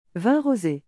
vin rosé , for which one does pronounce the last letter.